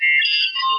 computer4.wav